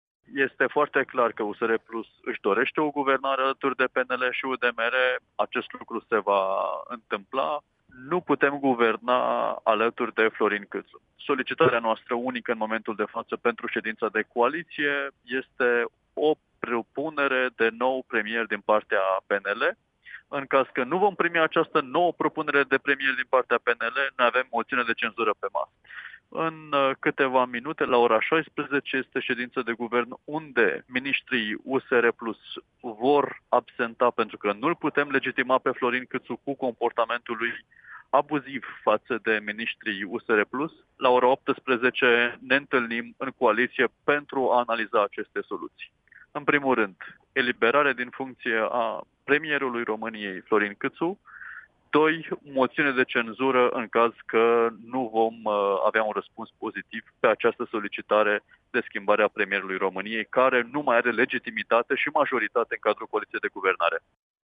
Pe de altă parte consemnăm și poziția USR PLUS formulată, de asemnea în exclusivitate pentru Radio Tg.Mureș de Iulian Bulai deputat USR PLUS – președintele Comisiei de Cultură: